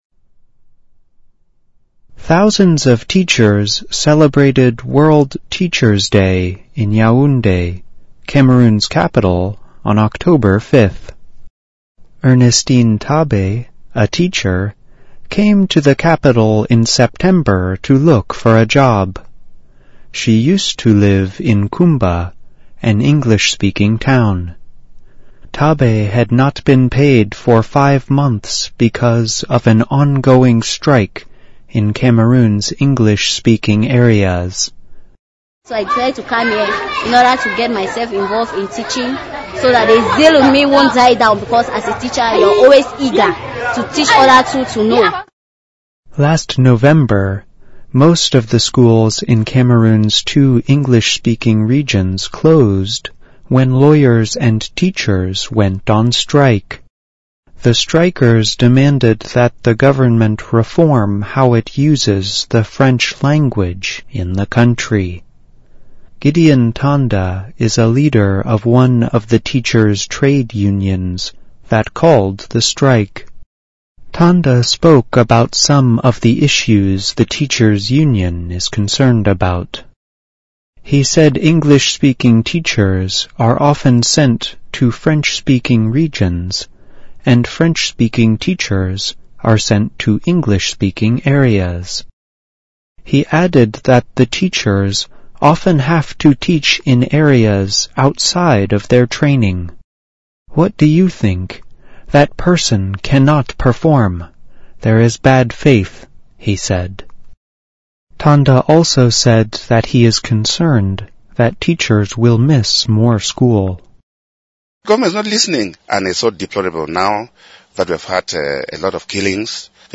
VOA慢速英语2017--喀麦隆英语区的教师继续罢工 听力文件下载—在线英语听力室